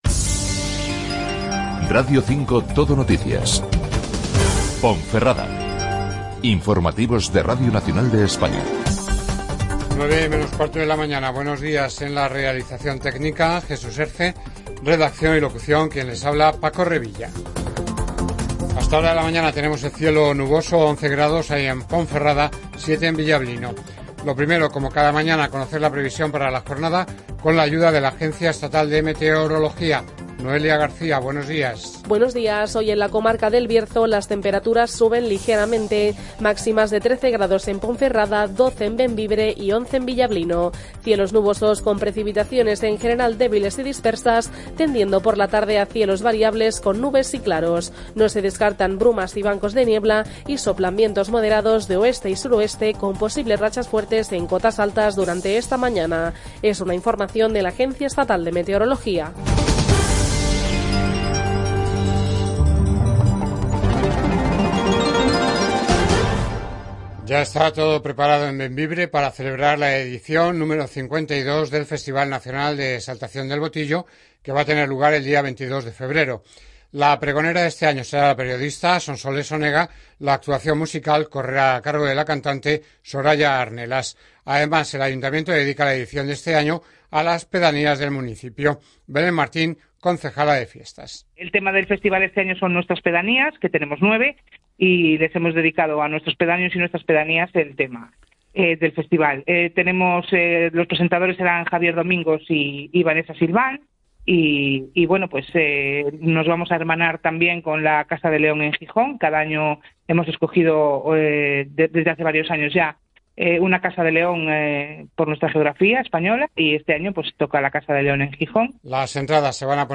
RNE Castilla y León Informativos